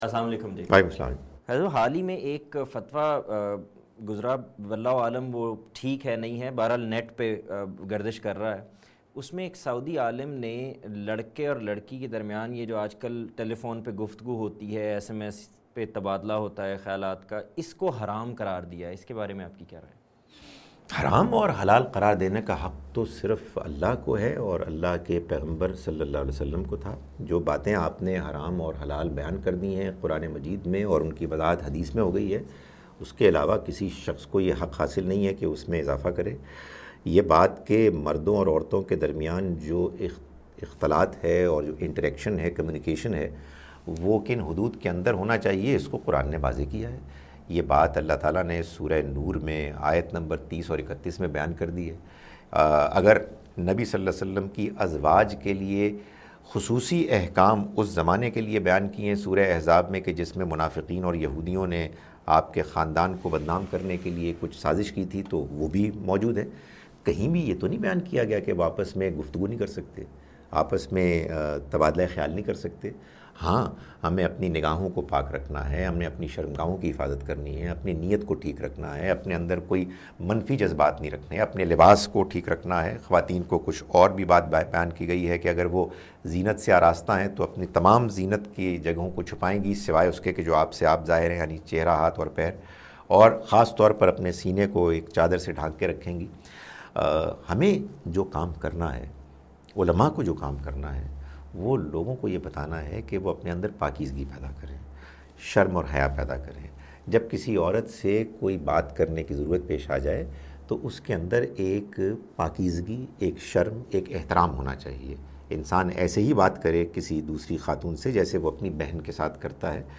Program "Ask A Question" where people ask questions and different scholars answer their questions